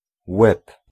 Ääntäminen
UK : IPA : /nʌt/ US : IPA : /nʌt/ UK : IPA : [nɐt]